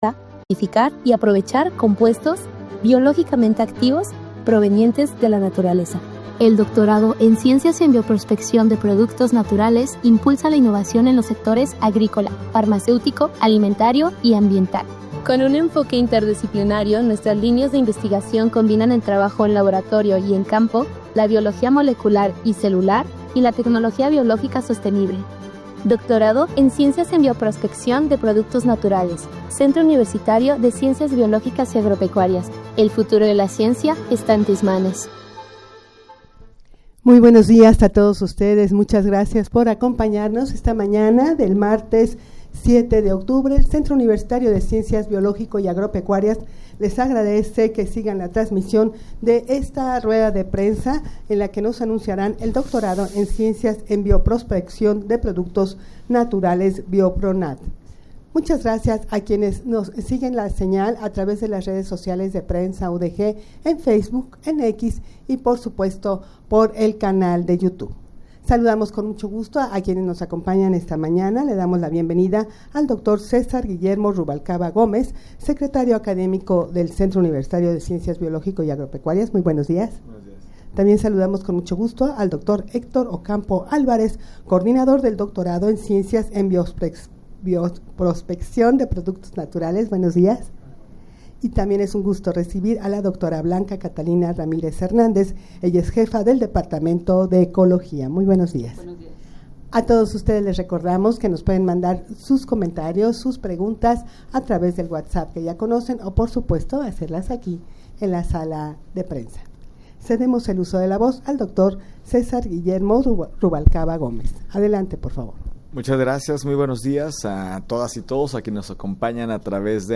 Audio de la Rueda de Prensa
rueda-de-prensa-en-la-se-presentara-el-doctorado-en-ciencias-en-bioprospeccion-de-productos-naturales-biopronat.mp3